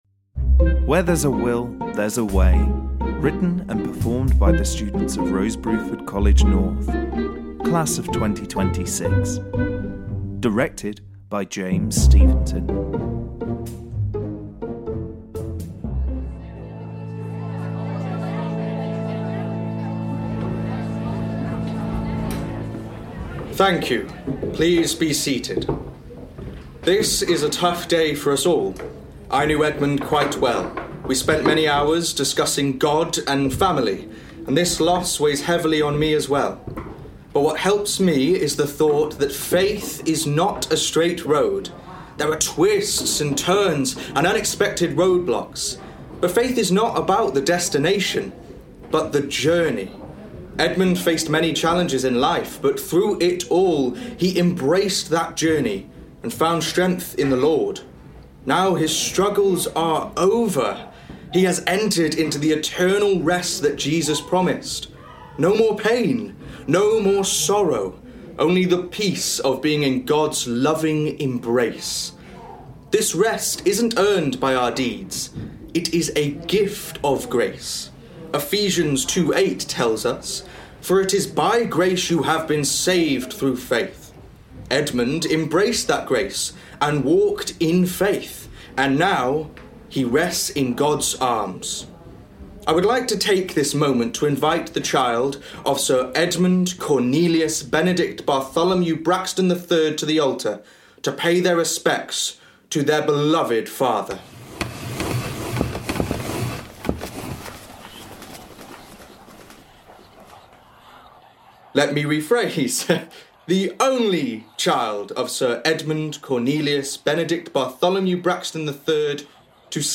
The Midweek Drama / WHERE THERE’S A WILL, THERE’S A WAY!
Recorded at: White Bear Studios, Manchester